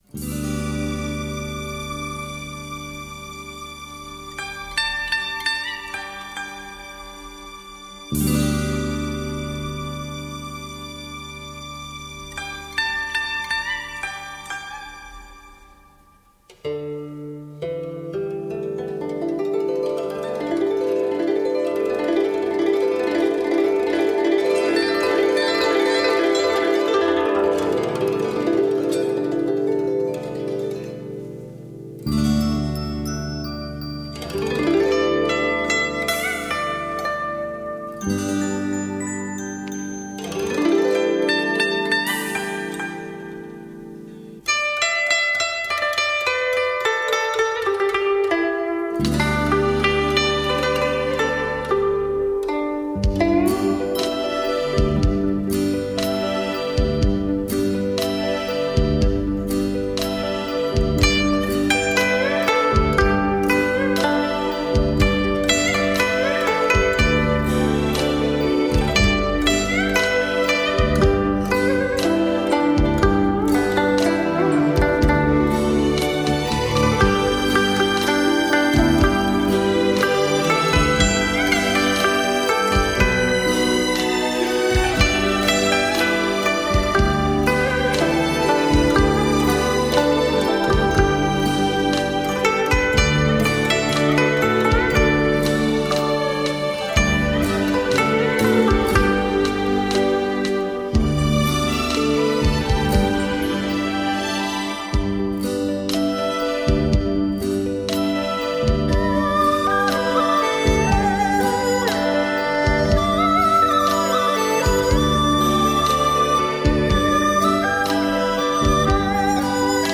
弦乐队伴奏：二胡 板胡 高胡 笛子 古筝 琵琶 巴乌 中阮 轮番演奏